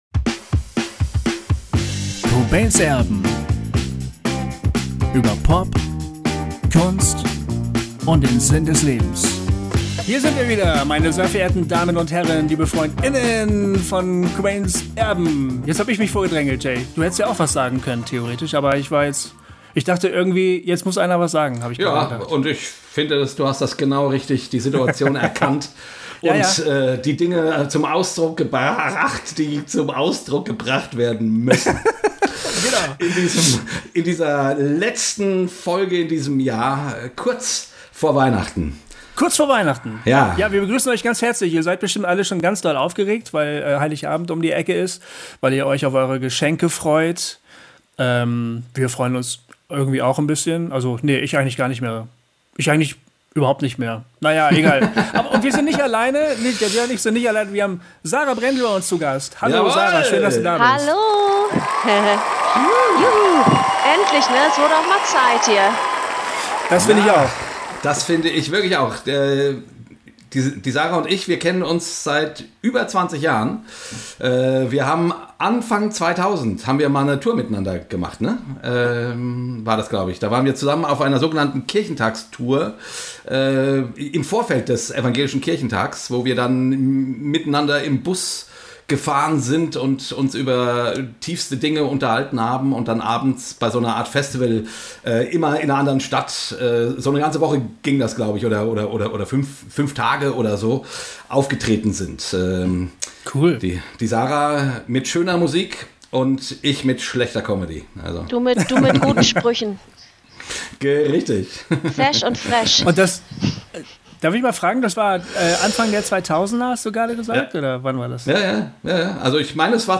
Mit diesem packenden Gespräch sagen wir: Danke fürs Zuhören und Begleiten im Jahr 21!